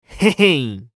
男性
熱血系ボイス～日常ボイス～
【楽しい2】